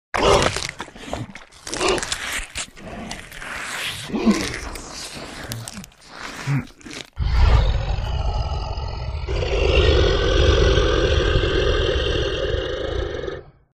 Монстр пожирает плоть с рычанием